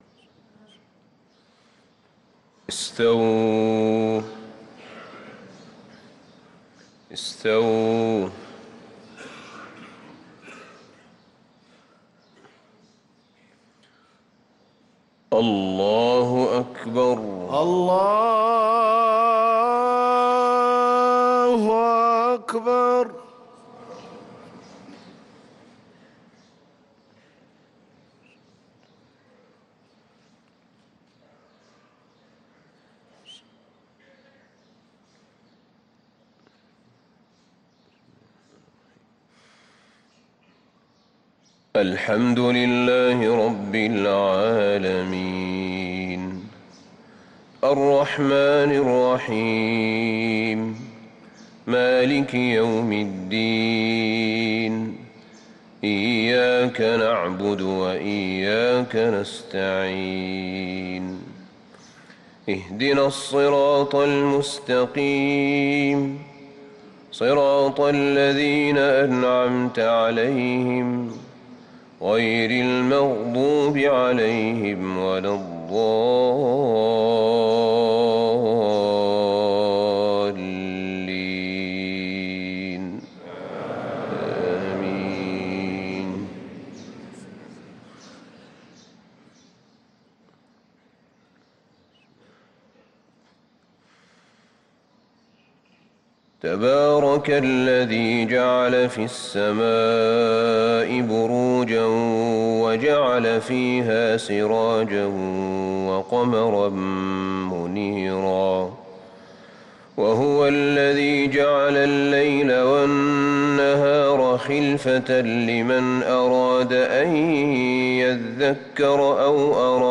صلاة الفجر للقارئ أحمد بن طالب حميد 24 شعبان 1444 هـ